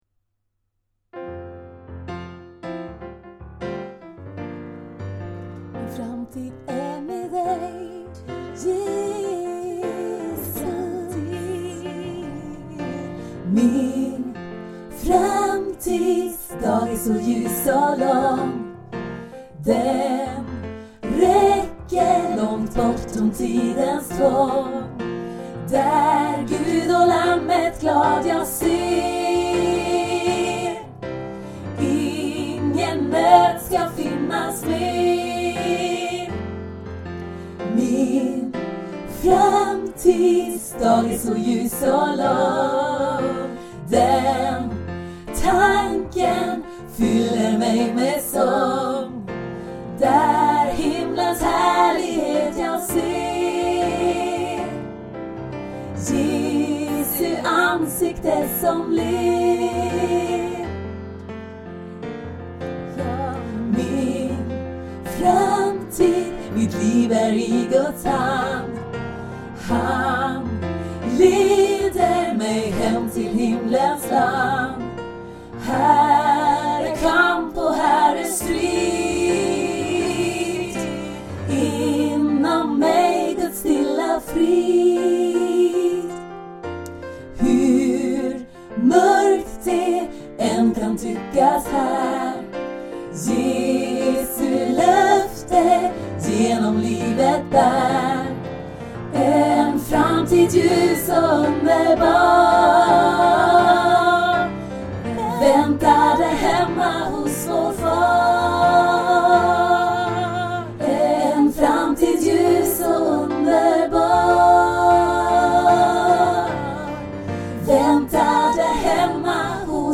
Här finns några moderna nya psalmer i enkla inspelningar.
En glad gospel-psalm, om tryggheten och vissheten i tron, och glädjen över tanken på himmelen. Hur än det ser ut här så får vi tröst av tanken på hur underbart det blir hemma hos Jesus.